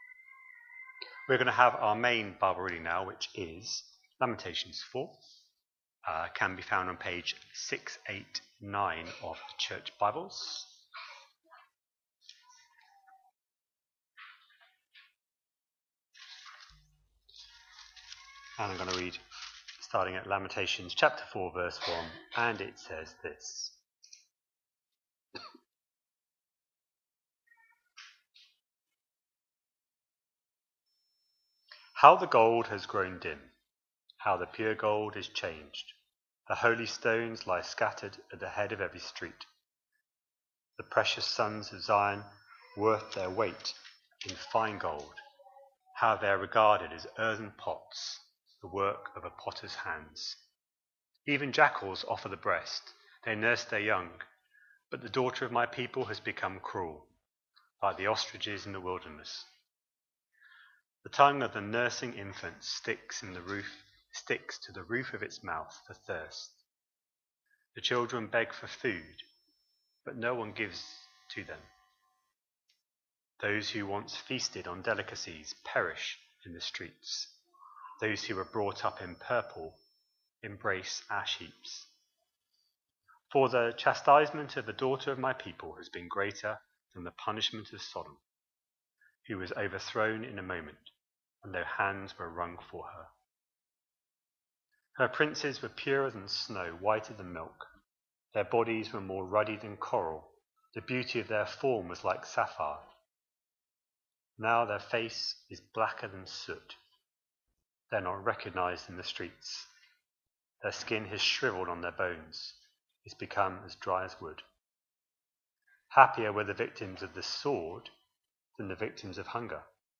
The latest sermons from Trinity Church Bradford.